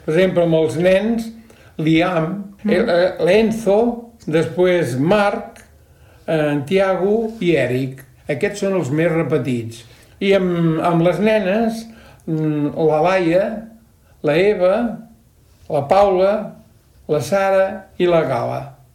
Liam en nens i Laia en nenes van ser els noms més posats a Tordera el passat 2023. El jutge de Pau, Esteve Matas, enumera els noms més posats entre els nens i les nenes de Tordera.